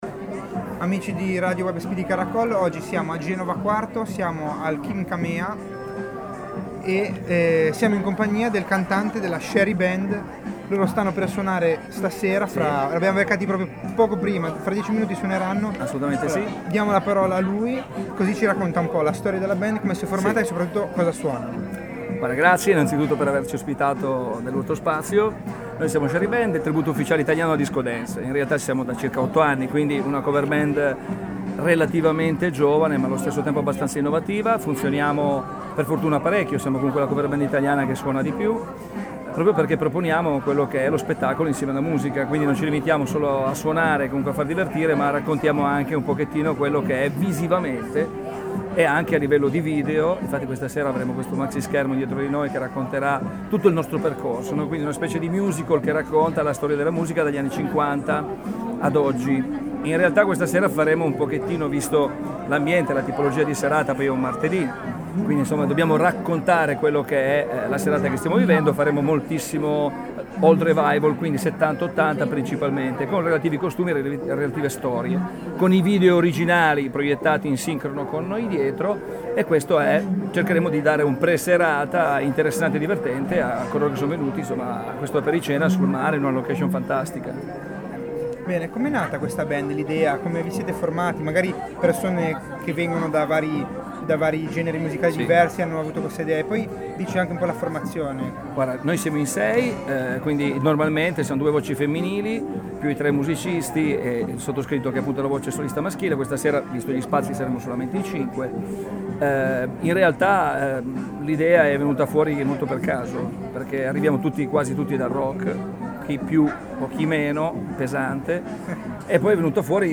Intervista a Shary Band